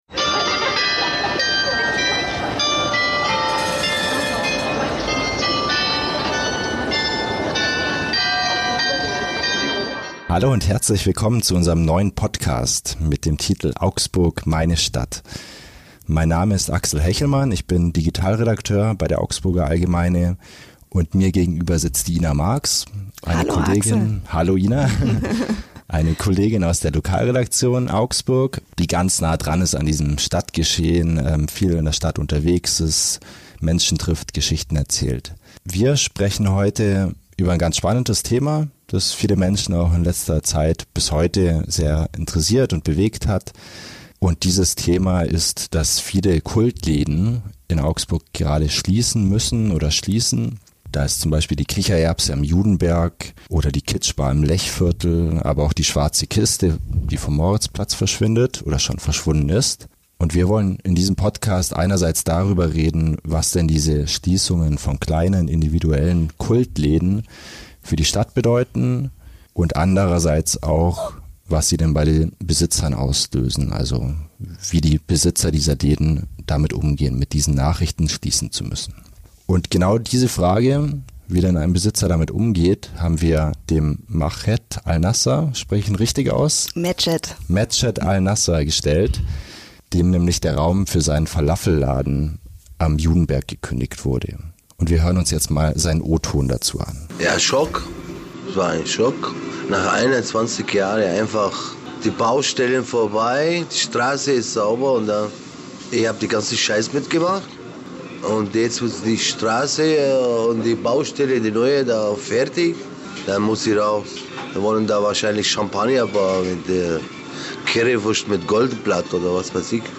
Im neuen Audio-Format der "Augsburger Allgemeine" kommen Menschen zu Wort, die etwas zu erzählen haben. In der ersten Folge von "Augsburg, meine Stadt" sprechen wir über ein Thema, das aktuell viele Menschen bewegt: Mehrere Kult-Lokale in der Innenstadt müssen schließen, darunter der Falafel-Imbiss Kichererbse und die Kitsch-Bar im Lechviertel.